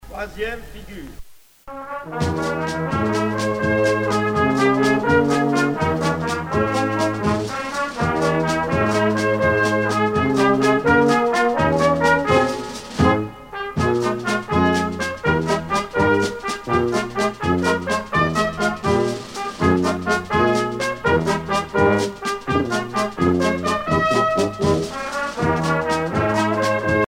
danse : quadrille